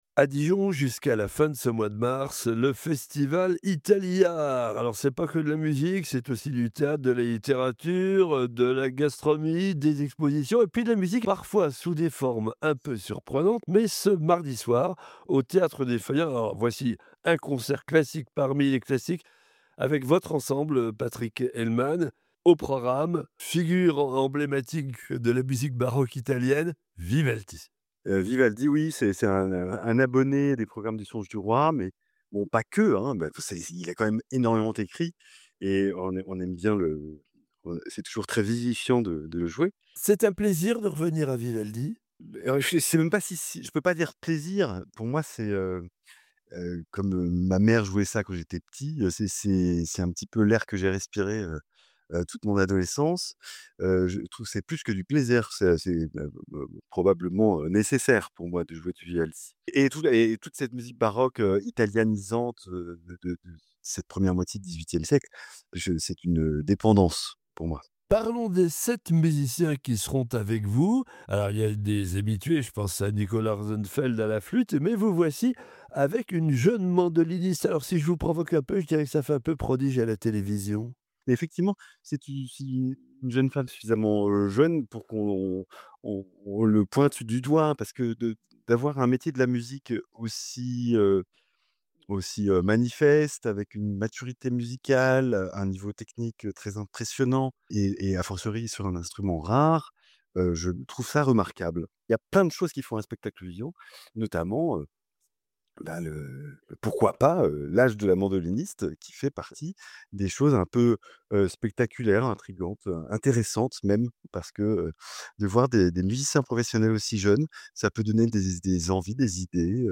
qui a répondu aux questions de BFC Classique…